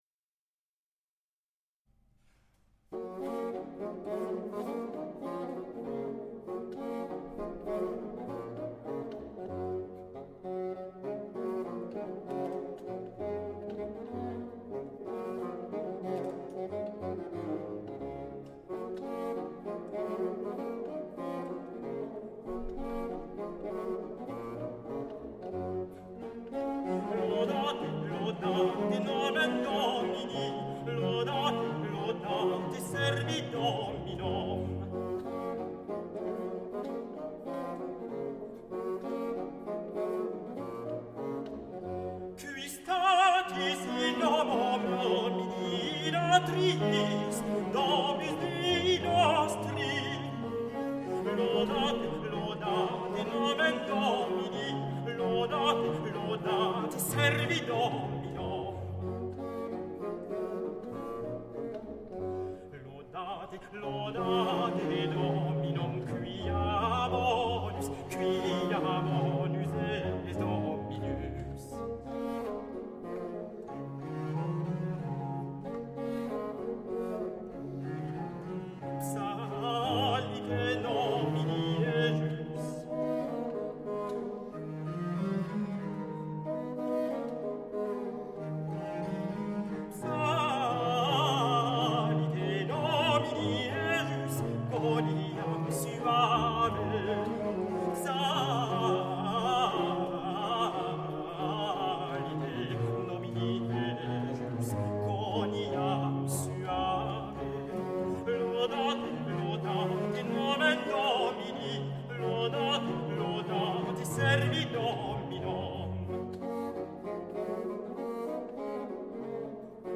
Grand motet
Group: Motet
Prelude - Recit de Taille - Choeur, avec Duos et Trios